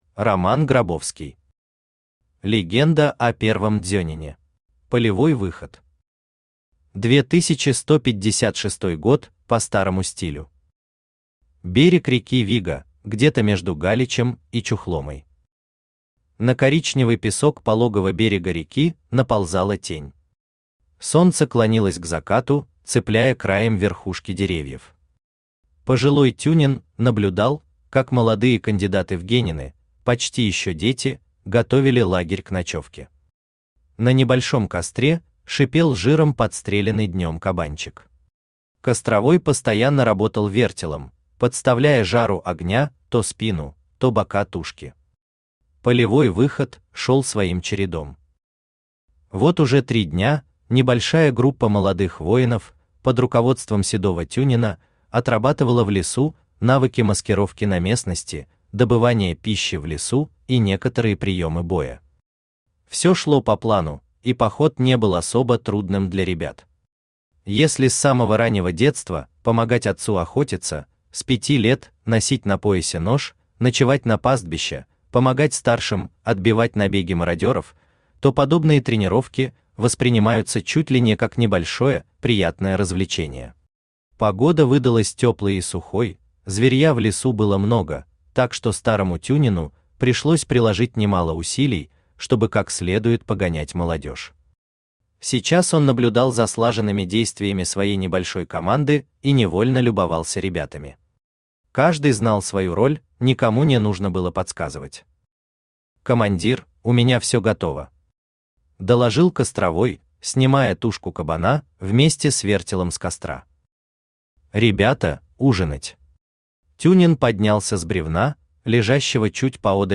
Аудиокнига Легенда о первом дзёнине | Библиотека аудиокниг
Aудиокнига Легенда о первом дзёнине Автор Роман Грабовский Читает аудиокнигу Авточтец ЛитРес.